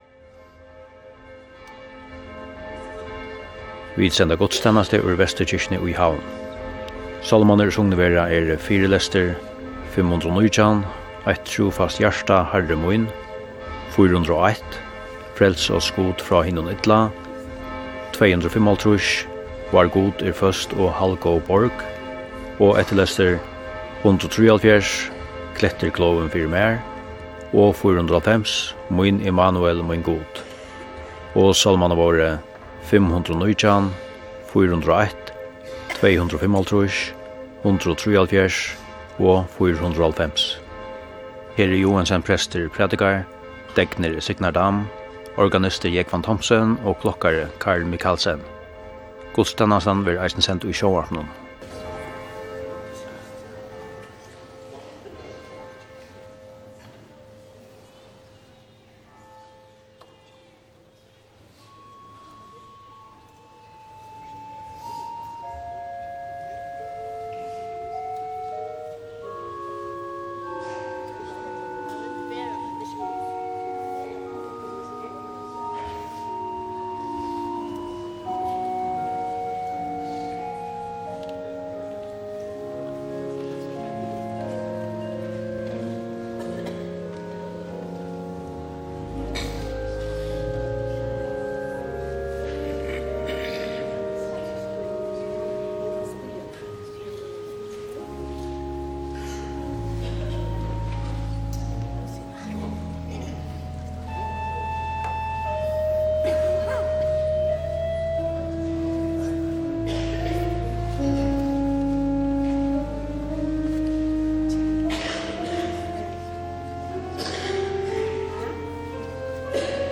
Útvarpið sendir beinleiðis gudstænastu hvønn sunnudag í árinum úr føroysku fólkakirkjuni.
Sagt verður, hvør er prestur, deknur, urguleikari og klokkari, og hvørjir sálmar verða sungnir.